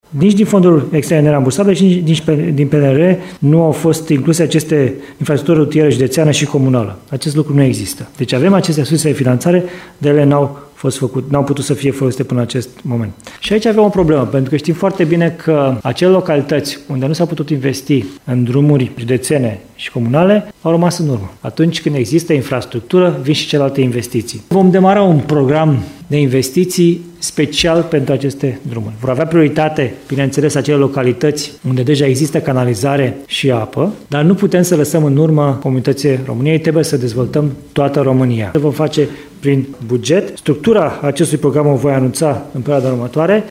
Guvernul României va demara un program de investiții special pentru drumurile comunale. Anunțul a fost făcut de premierul Florin Cîțu, prezent astăzi la Timișoara unde s-a aflsat într-o vizită de lucru.